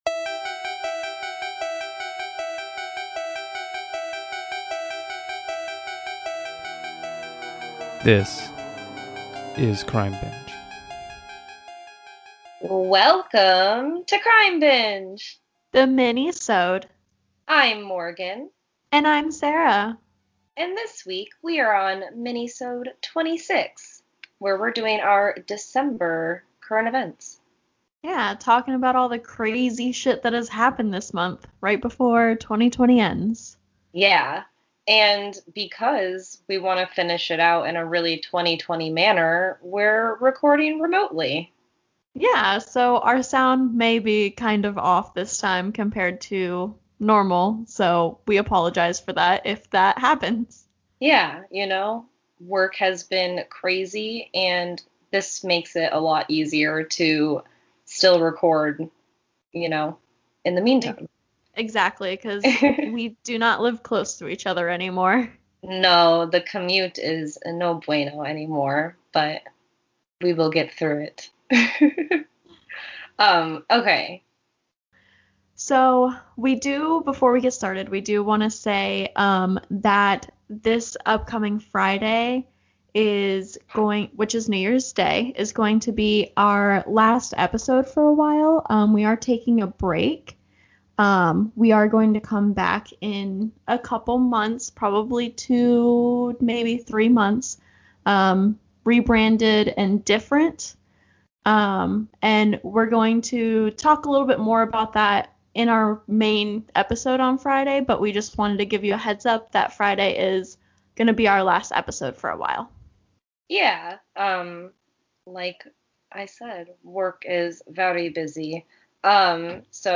This week on Crime Binge the girls bring you some true crime headlines from the final month in the year of hell, 2020.